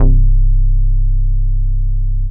VEC1 Bass Long 19 C.wav